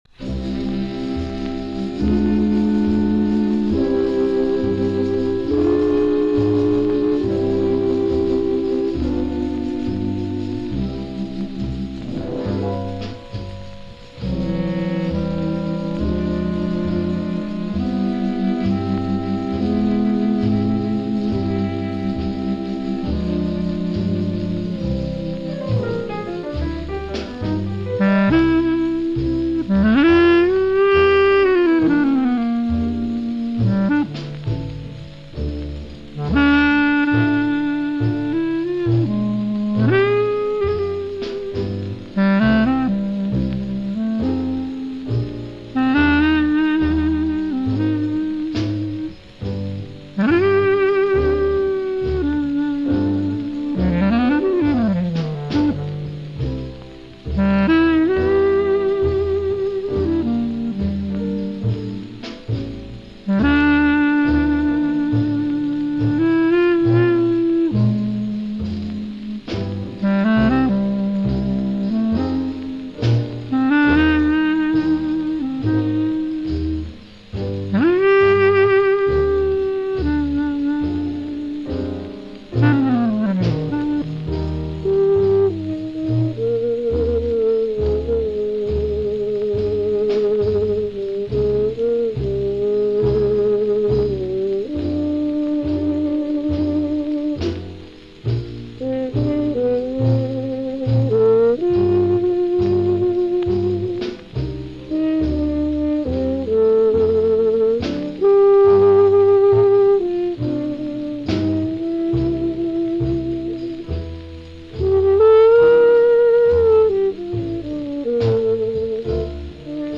small-group Jazz
but this particular recording comes via the original 78.